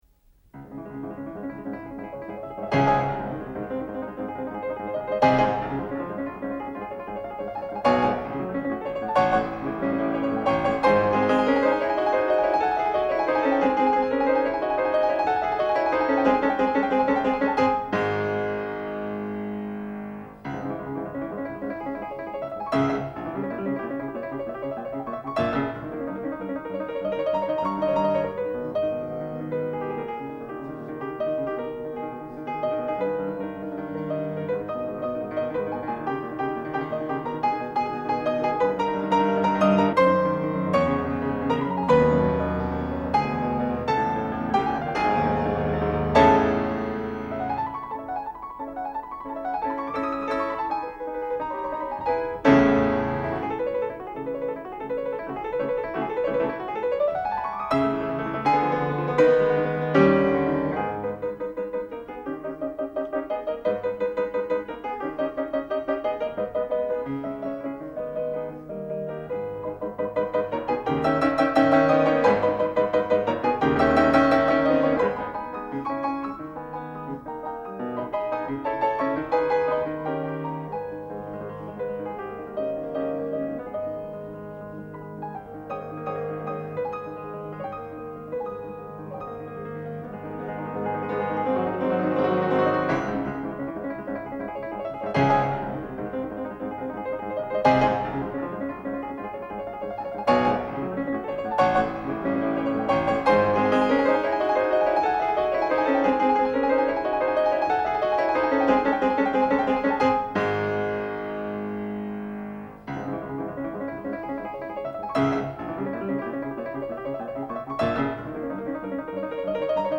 In the "Moonlight", the first movement is very slow, the second movement is a little livelier, and the last movement is very fast.
For those interested, this recording features Rudolf Serkin at the piano.
The third movement is particularly energetic, complex, and almost angry-sounding.